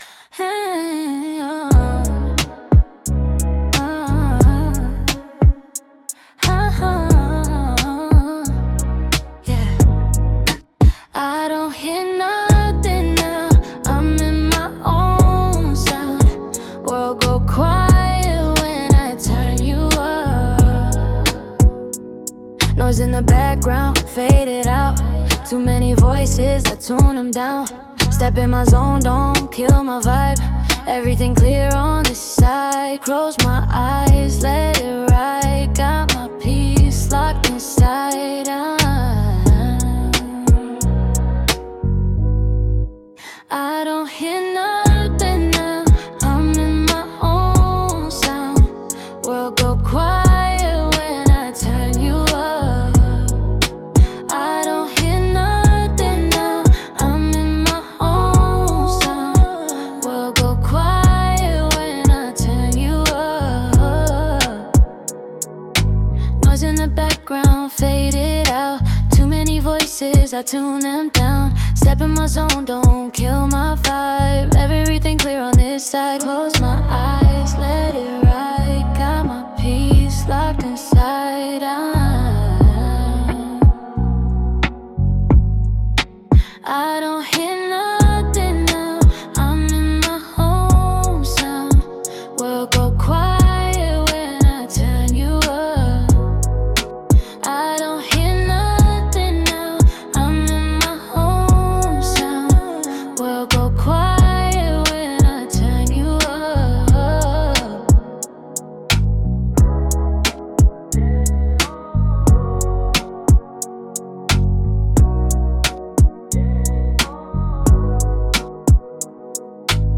The entire piece, including audio generated with Suno, was built end to end with AI.